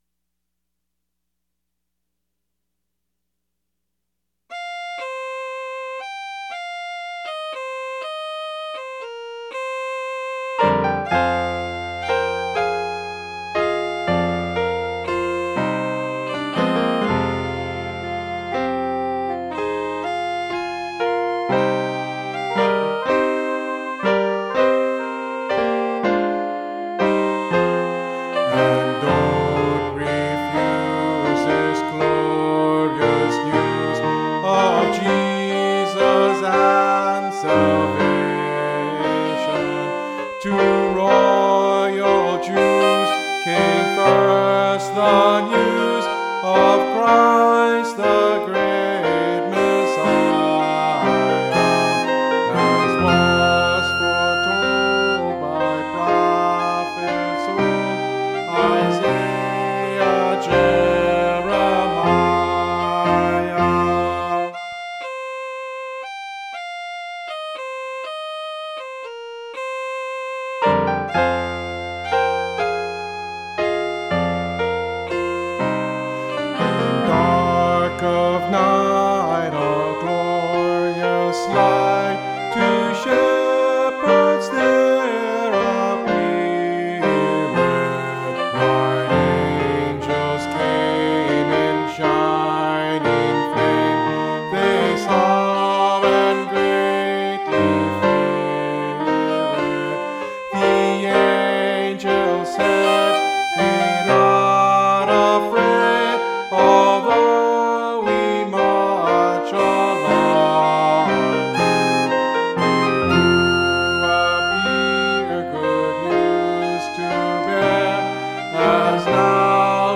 Mountain Christmas Carol